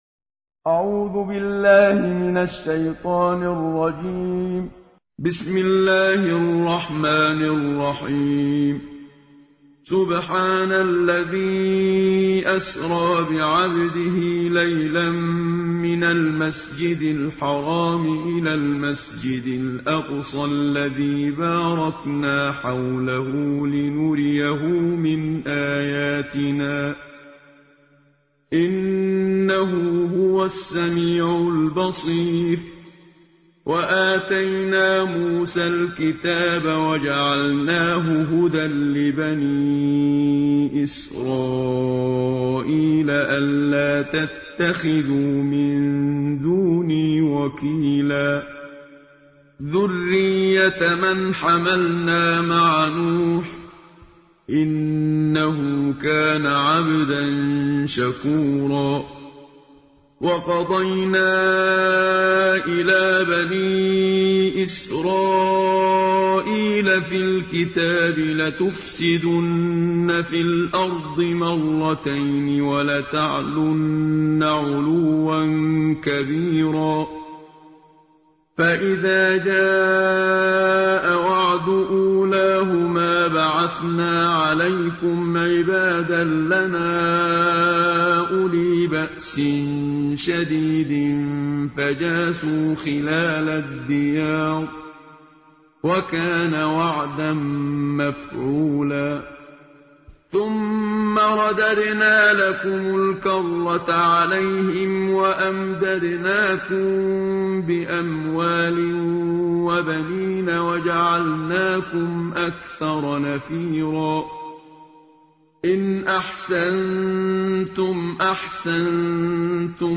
تلاوت جزء ۱۵ قرآن کریم با صدای استاد منشاوی/متن+ ترجمه